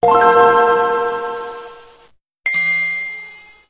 Gameboy Advance Startup.wav